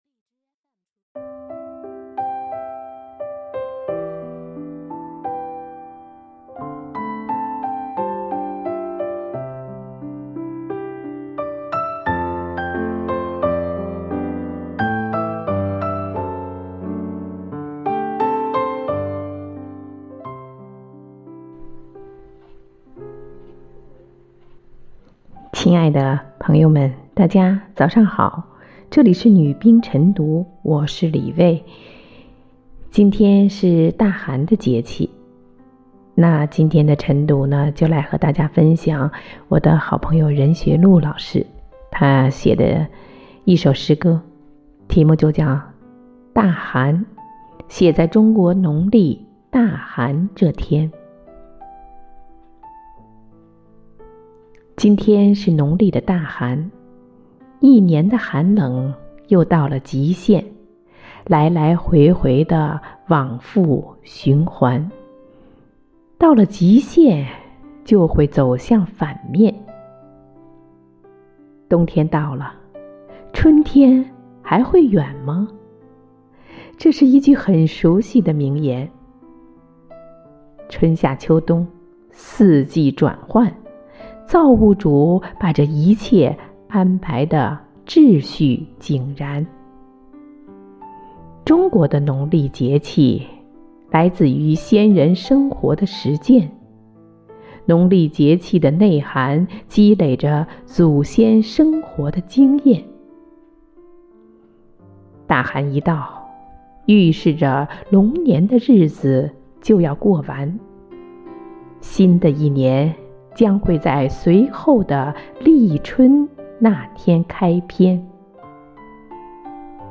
每日《女兵诵读》写在中国农历“大寒”这天